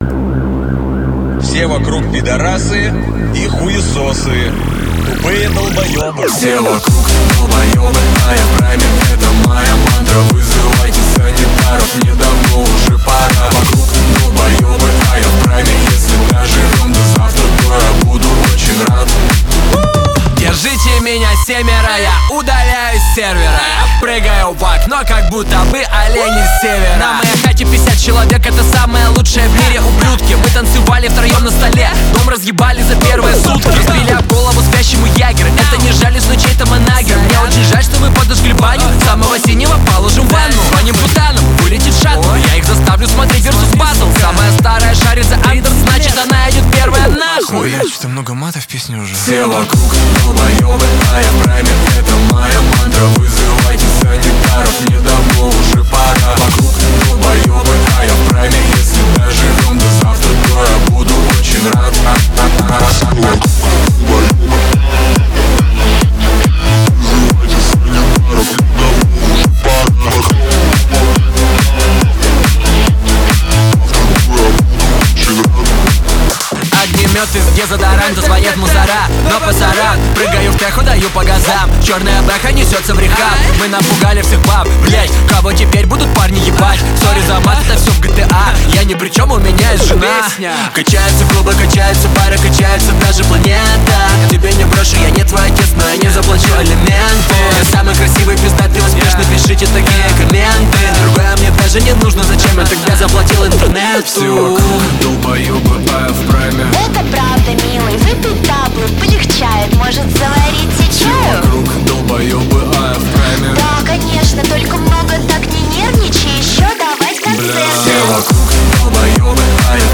Клубные хиты